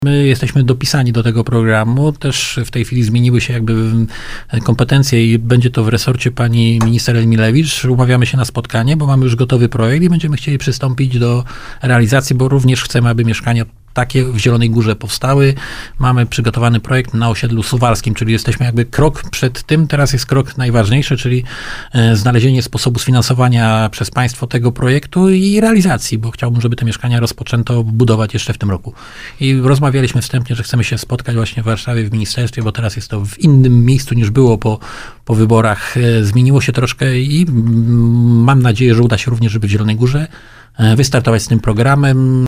Takie pytanie zadał słuchacz w audycji Prezydent na 96 FM.
W audycji Radia Index prezydent Janusz Kubicki podtrzymał tę deklarację. Wybrane jest już miejsce, gdzie mieszkania w ramach tego programu miałyby powstać.